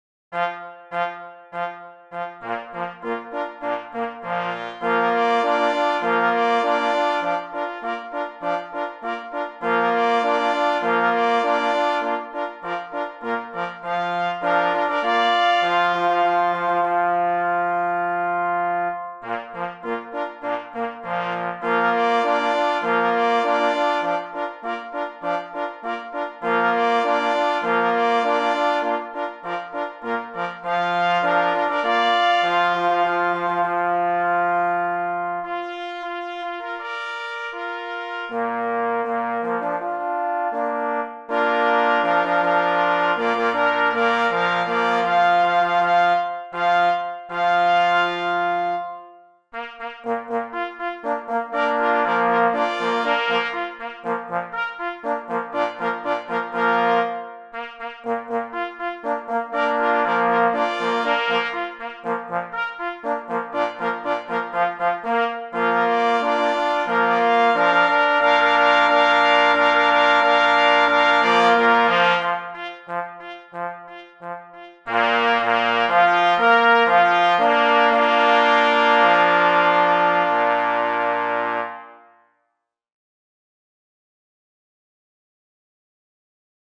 Opis zasobu: Humoreska: na 2 plesy […]
2 plesy i 2 parforsy